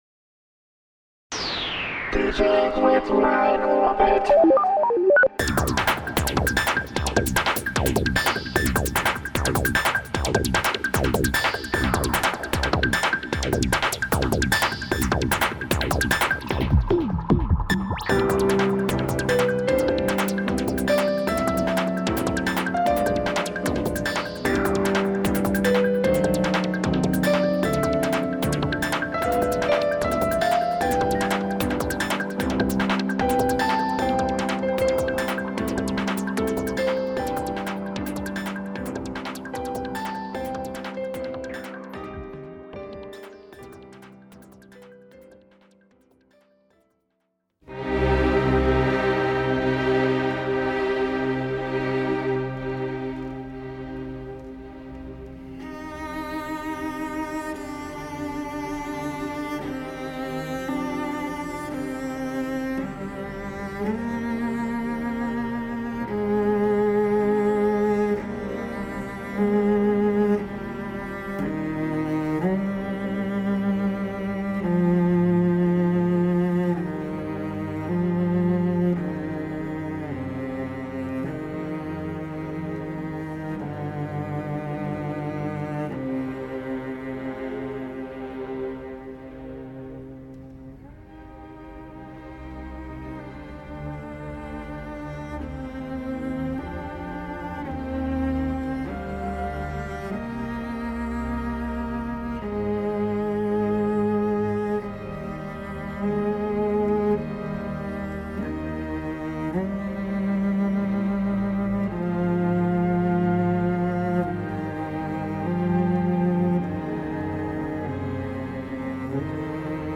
Paul Tortelier (cello) / Gabriel Faure: Elegie Op.24